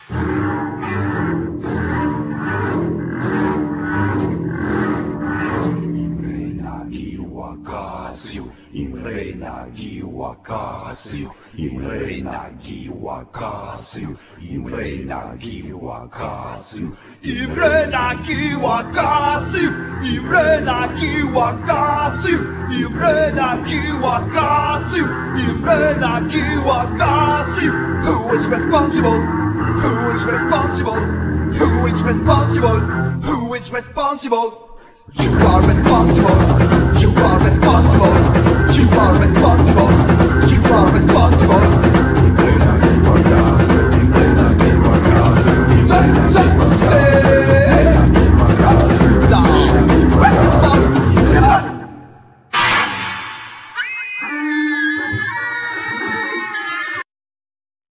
Guitar
Drums,Percussions
Contrabass
Saxophones,Synthesizer
Vocal
Bass